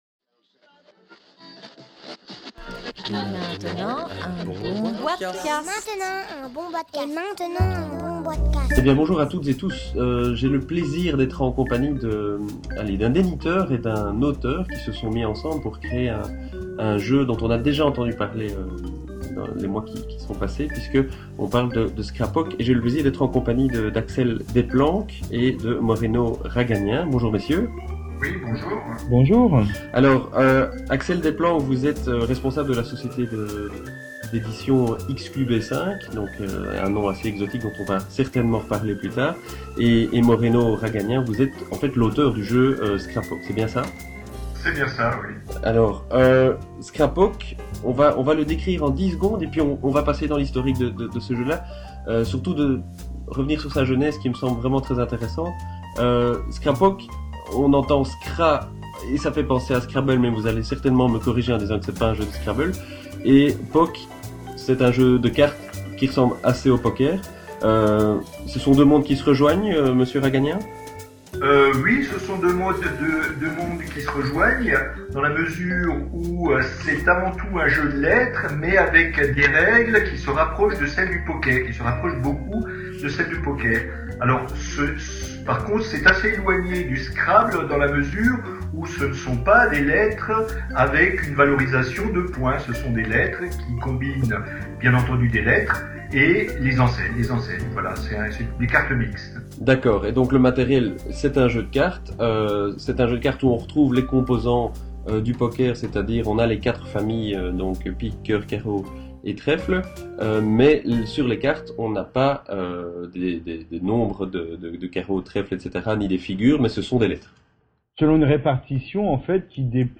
(enregistré via Skype et HijackThis – le 10 Avril 2011)